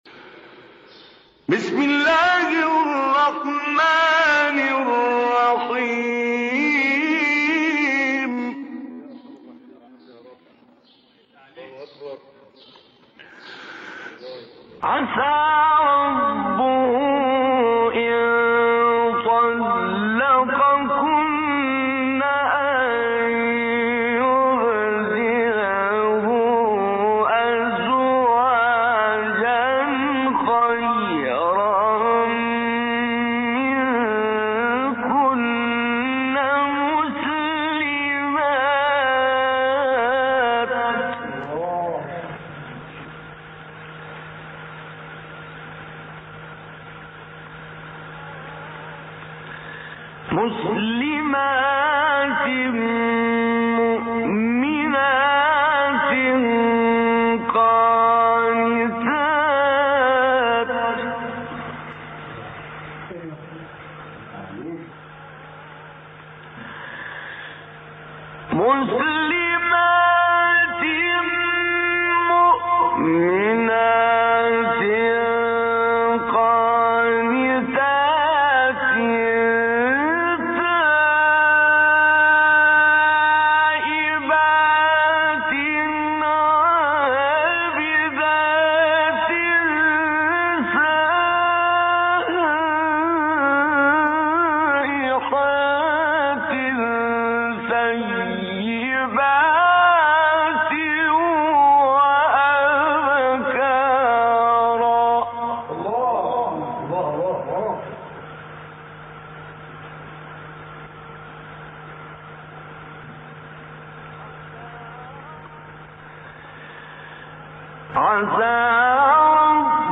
مقام : مرکب خوانی ( رست* بیات)